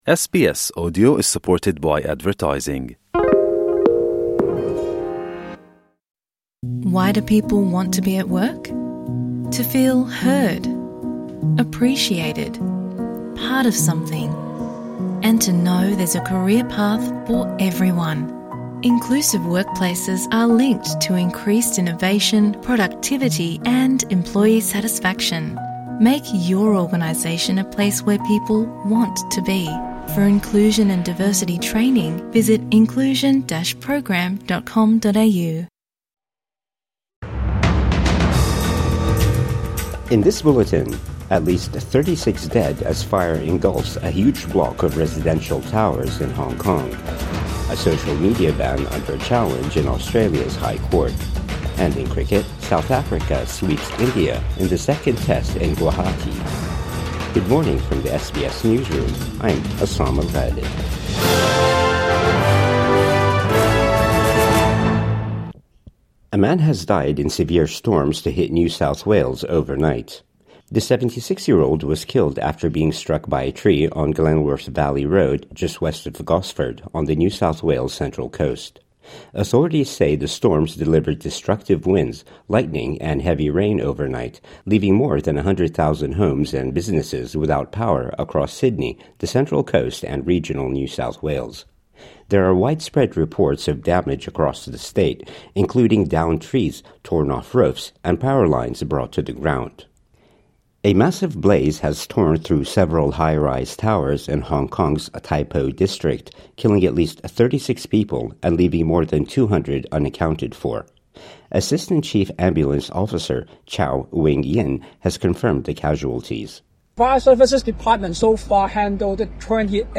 Death toll climbs in Hong Kong apartment fire | Morning News Bulletin 27 November 2025